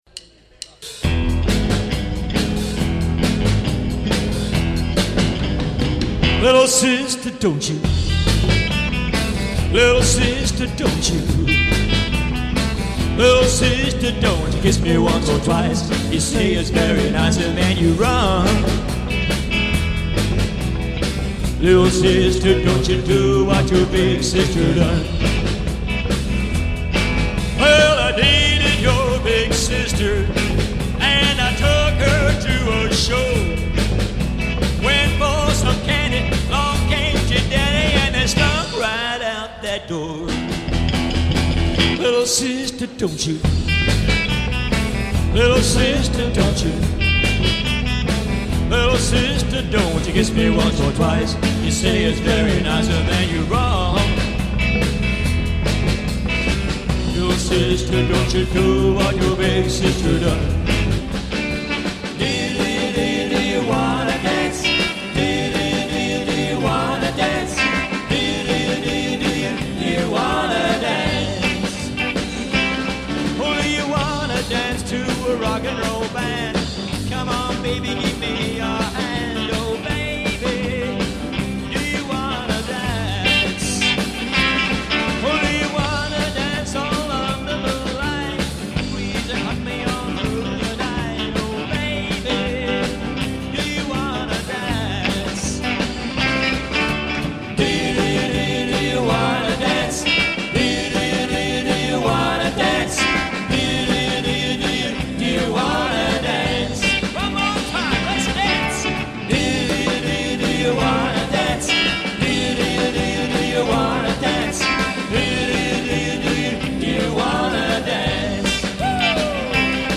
Classic Rock as well as Rock ‘n’ Roll & Jive
Classic Rock music or Rock ‘n’ Roll